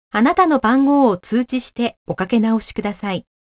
■着信拒否アナウンス２